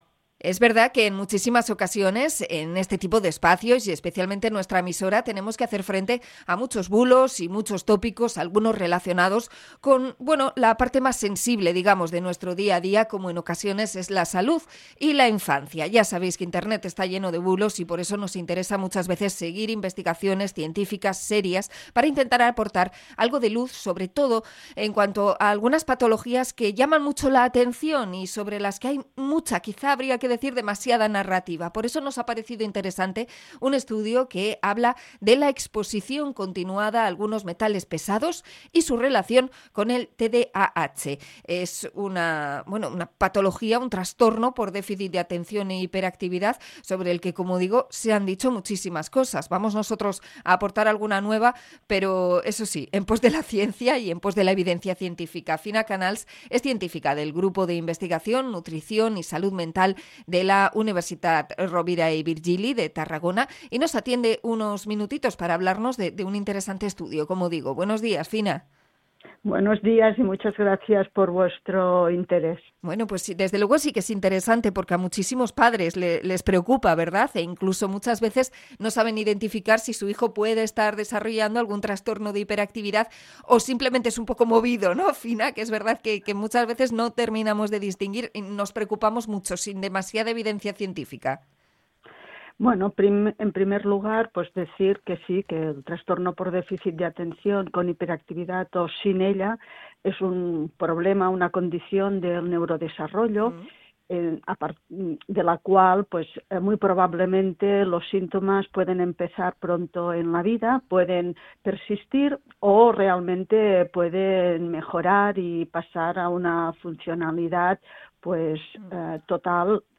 Entrevista a investigadora en nutrición y salud mental